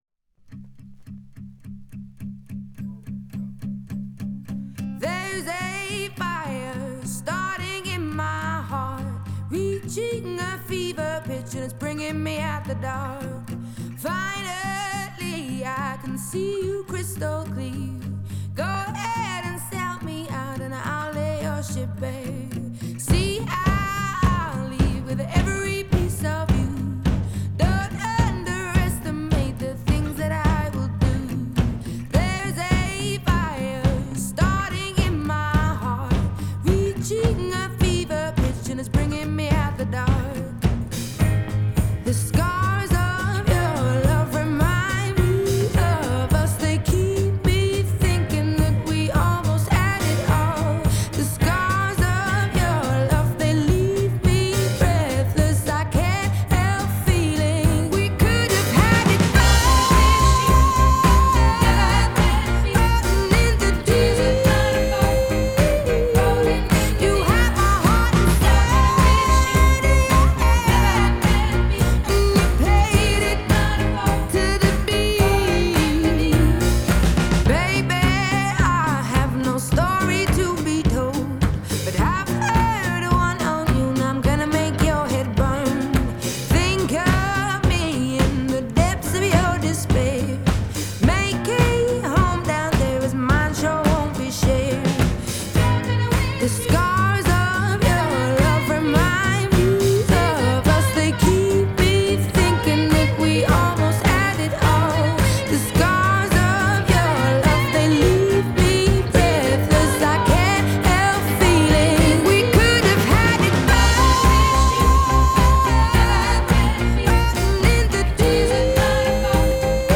Genre: Pop, Blues